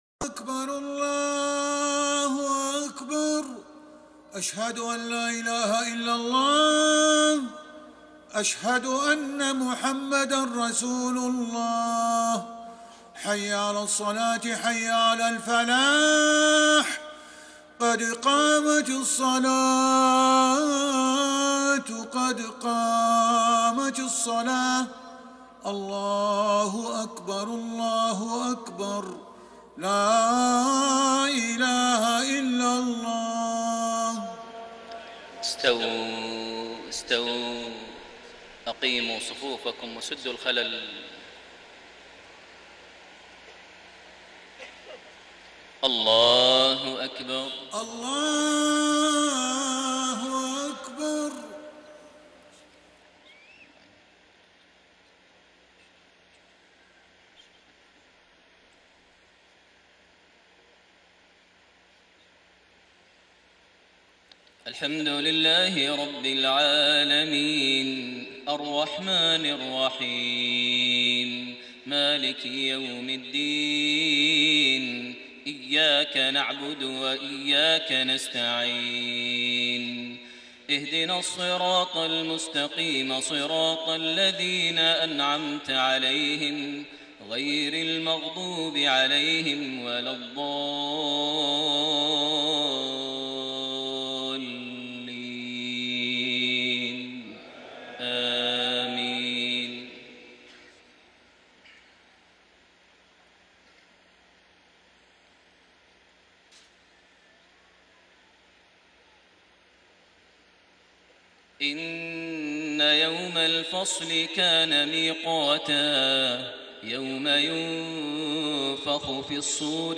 Isha prayer from Surah An-Naba > 1433 H > Prayers - Maher Almuaiqly Recitations